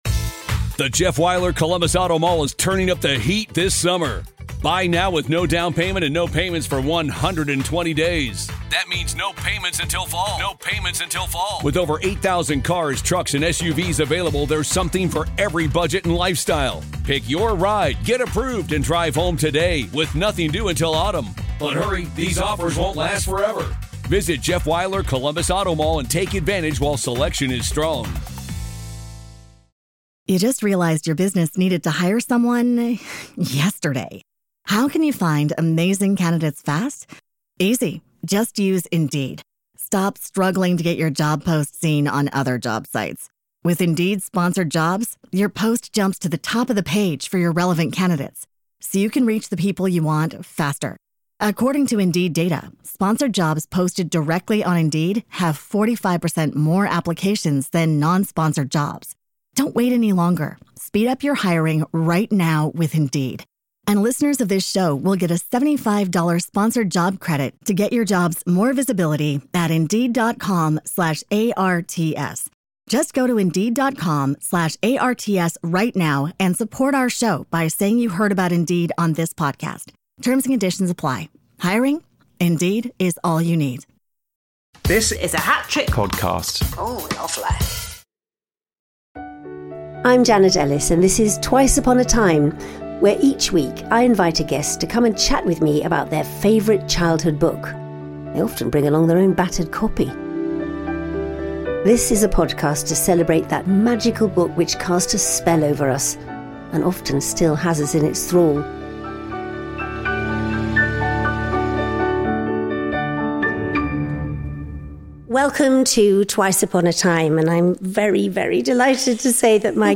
In this show, recorded LIVE at the Lyric Theatre Hammersmith, Janet is delighted to welcome musician and all round superstar Sophie Ellis-Bextor to discuss her choice of book and find out if parental reading during her childhood was really up to scratch!